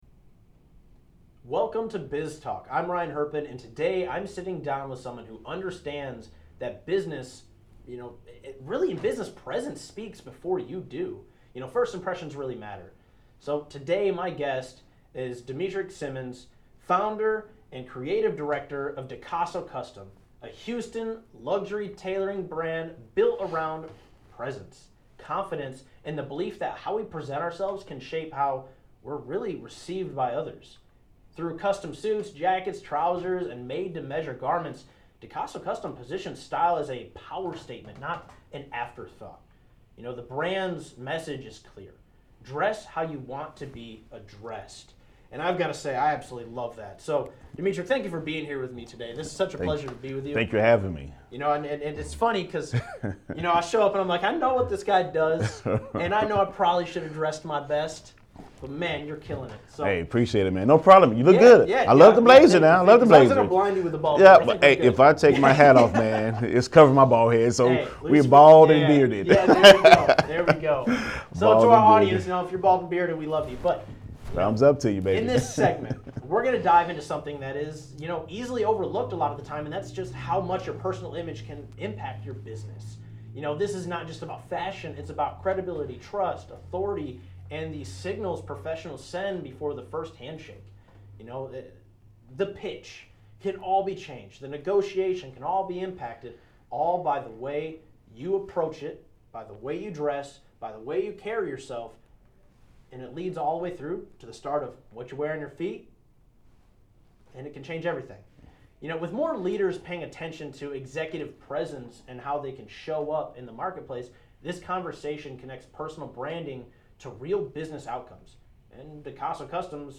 From first impressions to executive presence, this conversation dives into how the way you dress influences credibility, confidence, and opportunity.